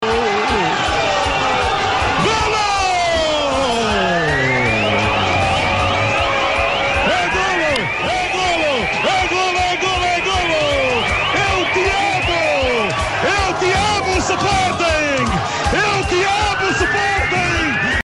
É o último relato de Jorge Perestrelo.